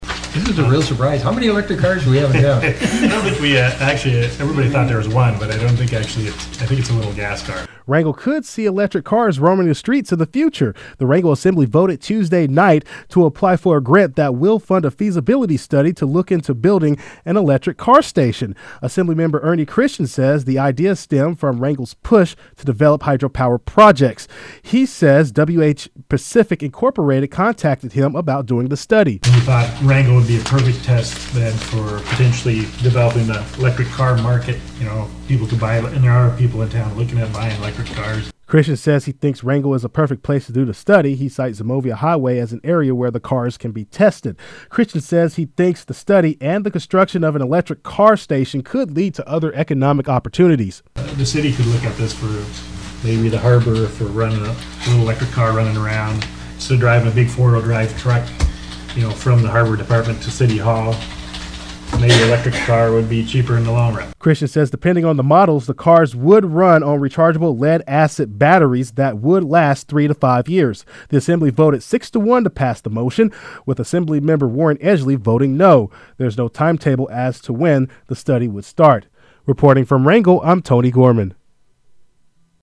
© Copyright, Wrangell Radio Group Did you appreciate this report?